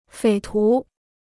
匪徒 (fěi tú): bandit; gangster.